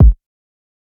Metro Simple Kick.wav